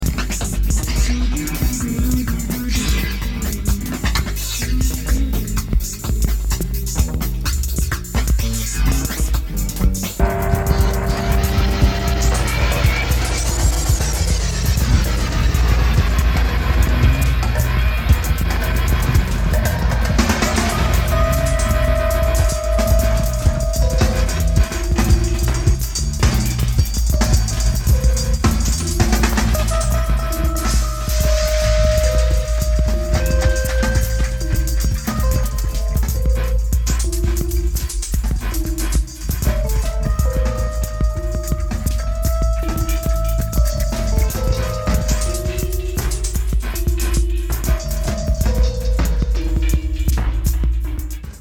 Nada polido, mas impoluto.